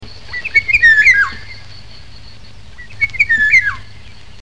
Loriot d'Europe
Oriolus oriolus
Ce bel oiseau migrateur hiverne en Afrique du sud et de l'est. Dès le début du mois de mai, il trahit sa présence par son sifflement court mais mélodieux et puissant.
loriot.mp3